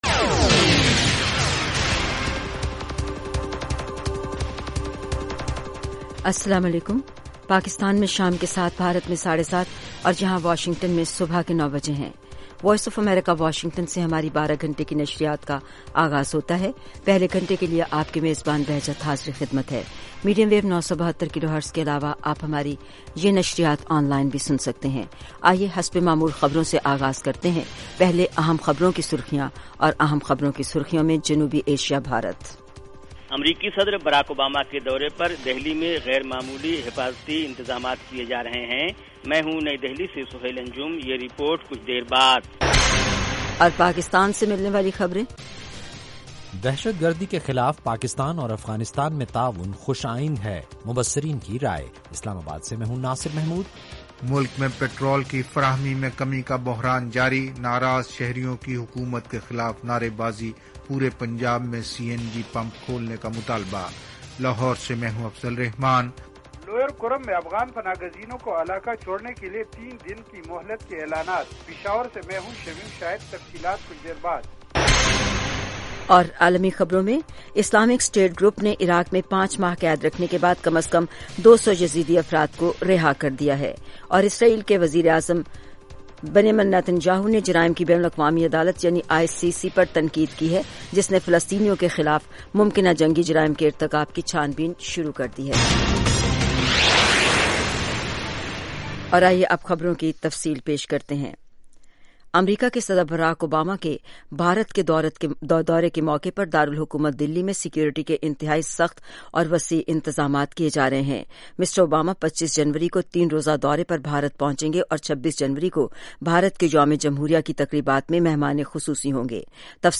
7:00PM اردو نیوز شو اس ایک گھنٹے میں دن بھر کی اہم خبریں اور پاکستان اور بھارت سے ہمارے نمائندوں کی روپورٹیں پیش کی جاتی ہیں۔ اس کے علاوہ انٹرویو، صحت، ادب و فن، کھیل، سائنس اور ٹیکنالوجی اور دوسرے موضوعات کا احاطہ کیا جاتا ہے۔